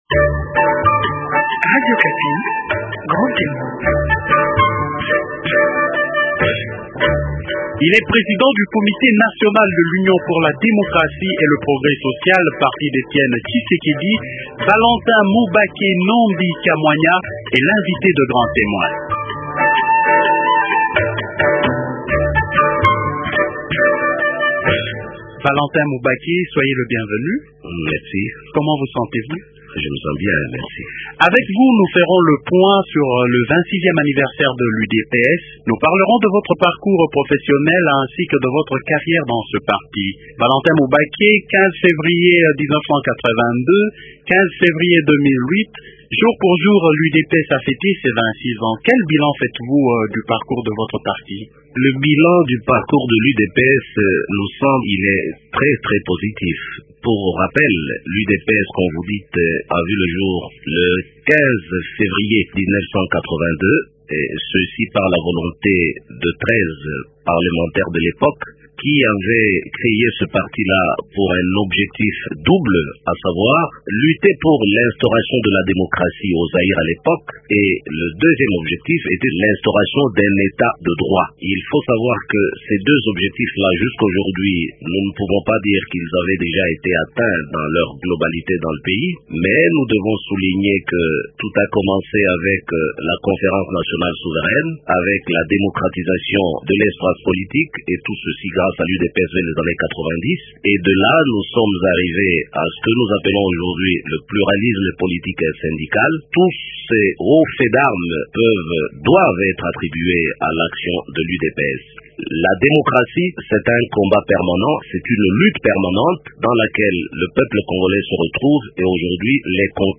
Il annonce aussi que dans ses perspectives, le parti d’Etienne Tshisekedi fait de la conquête du pouvoir son cheval de bataille. Comment et quand va-t-il conquérir ce pouvoir ? Réponse dans cet entretien.